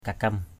/ka-kʌm/ (t.) lộn (hỏng) = couvé. baoh manuk kakem _b<H mn~K kk# trứng gà lộn (trứng gà hư) = œuf de poule couvé.